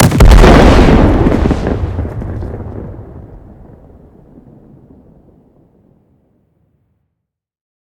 nuclear-explosion-1.ogg